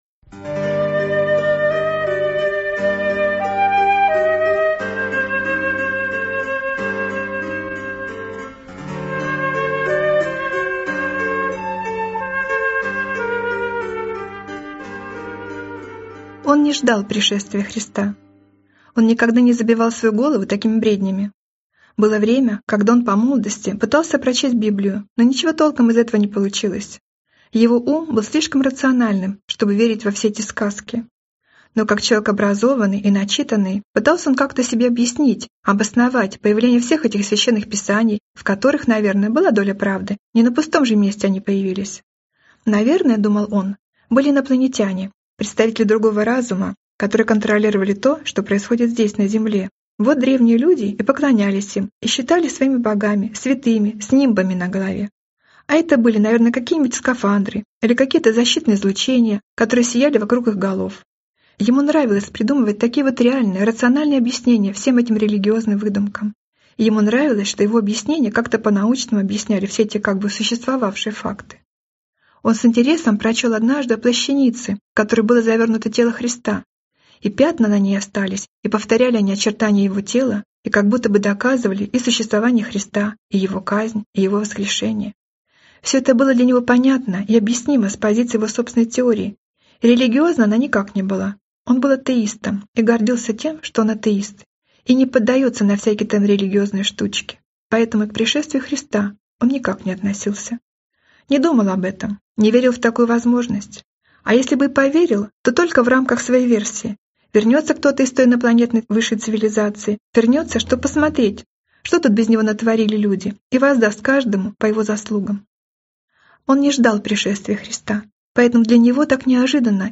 Аудиокнига Пришествие Христа | Библиотека аудиокниг